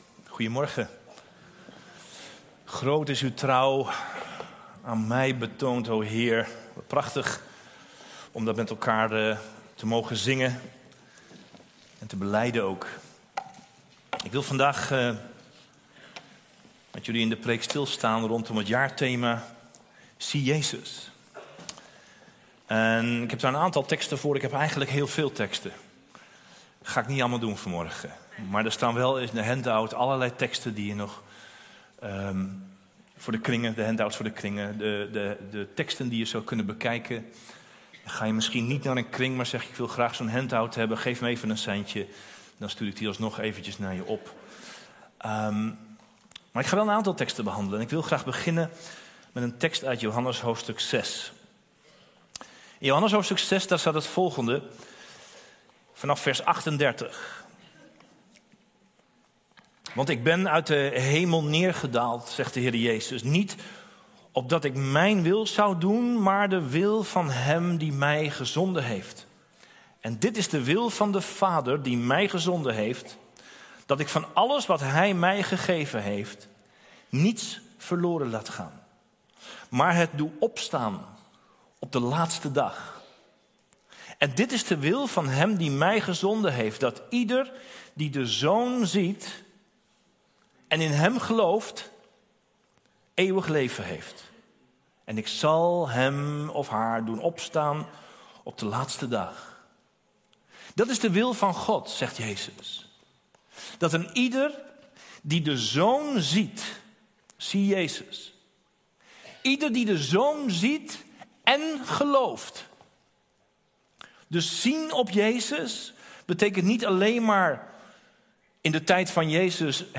Passage: Johannes 6:38-40, 11:17-45 Soort Dienst: Reguliere dienst « Wandelen in relatie met God en elkaar Israël en de gemeente